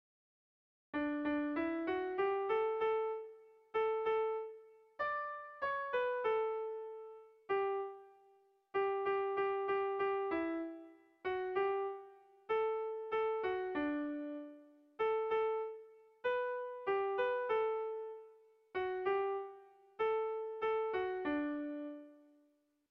Dantzakoa
AB